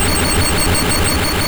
P3D / Content / Sounds / Battle / Attacks / Electric / Thunderbolt.wav
Thunderbolt.wav